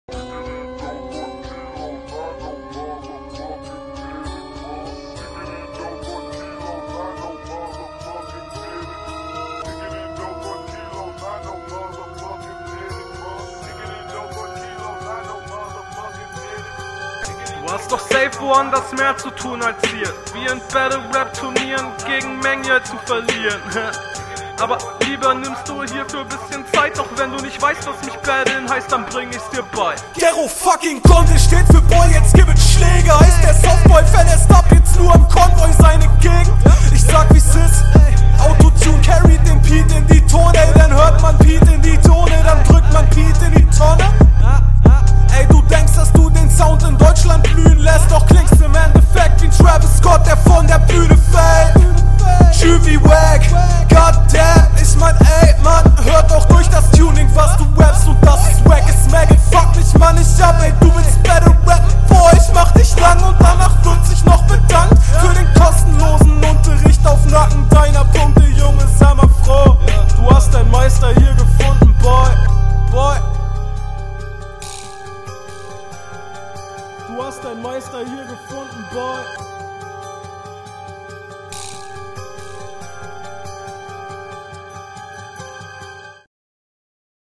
Flowtechnisch ganz ok aber im großen und ganzen sehr Standard.
Beat is schonmal geil!